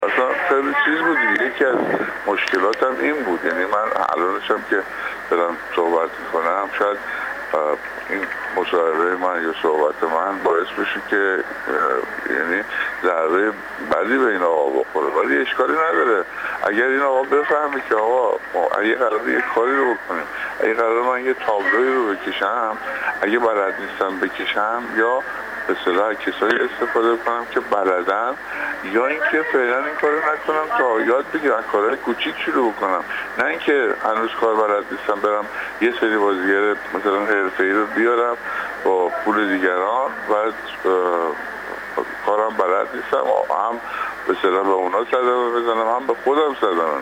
پرویز فلاحی‌پور در گفت‌وگو با ایکنا: